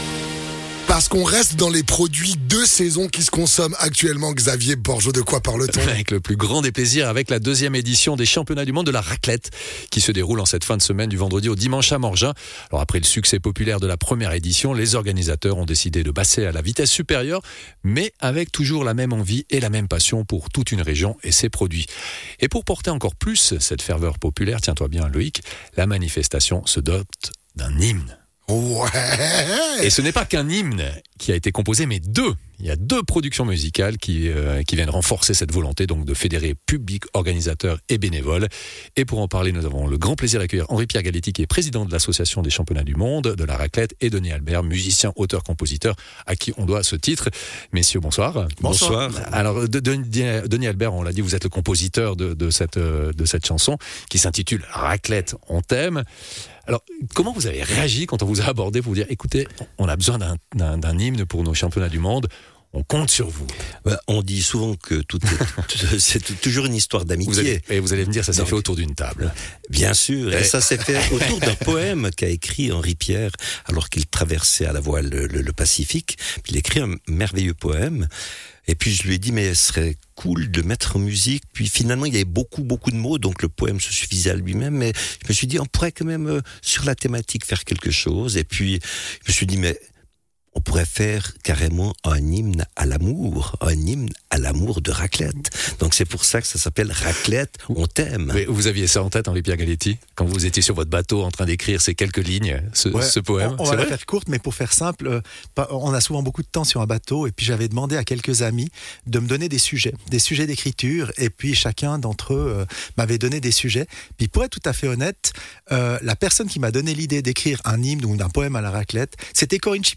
musicien, auteur, compositeur